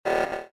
SFX_Alert_Short.mp3